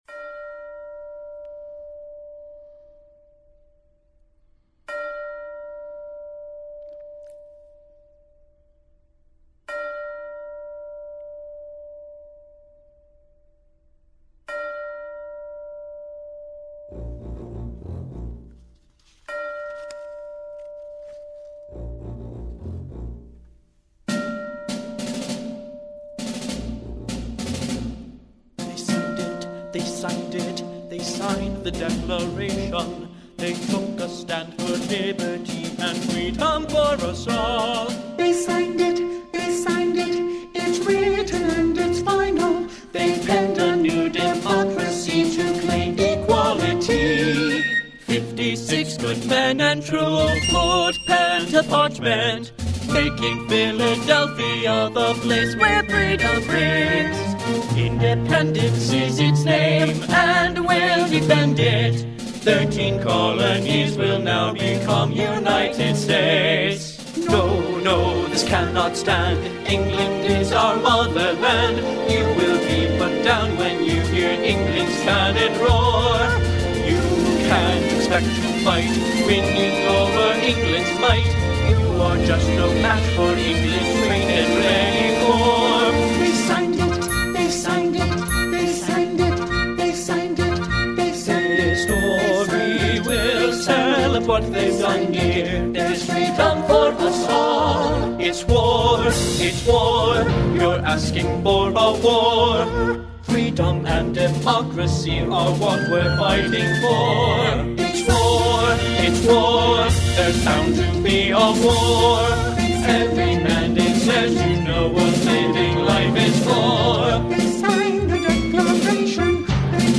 These recordings are rough demos.